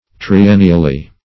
triennially - definition of triennially - synonyms, pronunciation, spelling from Free Dictionary Search Result for " triennially" : The Collaborative International Dictionary of English v.0.48: Triennially \Tri*en"ni*al*ly\, adv. Once in three years.
triennially.mp3